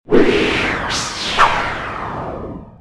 droneLaunch.wav